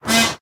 Efecto cómico: trompetilla
trompeta
cómico
Sonidos: Especiales